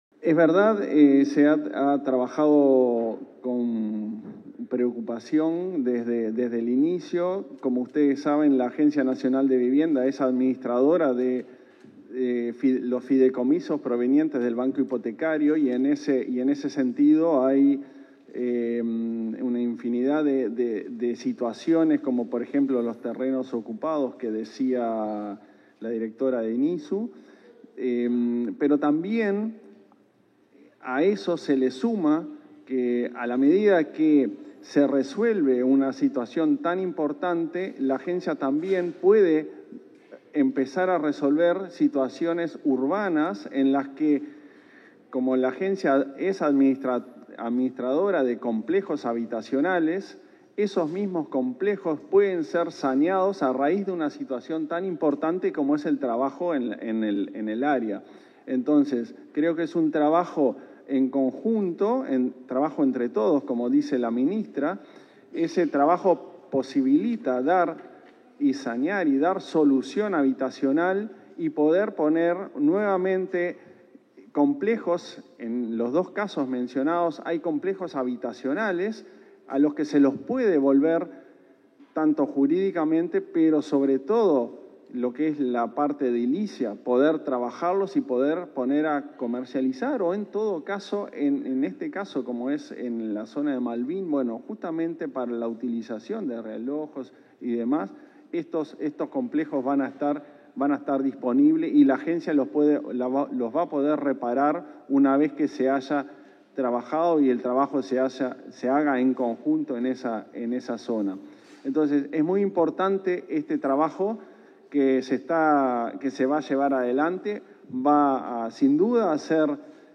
Conferencia de prensa por firma de convenio entre el MTOV y la ANV
En el marco de la firma de un convenio entre la Dirección de Integración Social y Urbana, del Ministerio de Vivienda y Ordenamiento Territorial (MVOT), y la Agencia Nacional de Vivienda (ANV), este 15 de agosto, para implementar el plan Avanzar, se expresaron el subsecretario del MVOT, Tabaré Hackenbruch, y el presidente de la ANV, Klaus Mill.